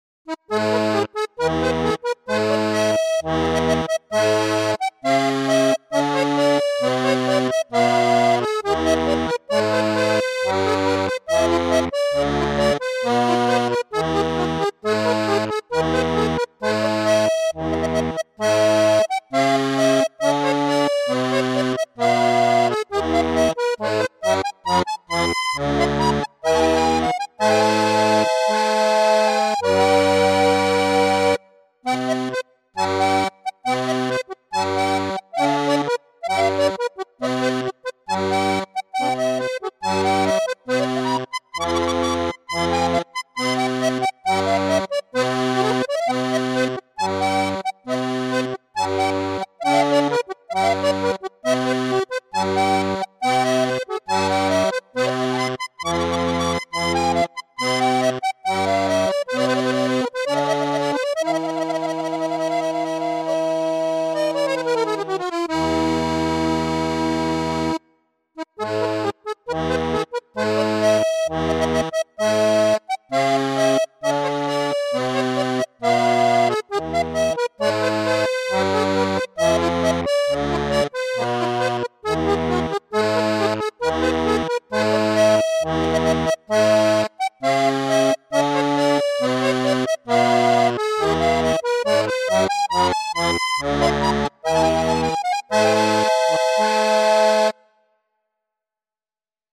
メロディはストリングスが担当していたので、もっと流れるような曲調だった。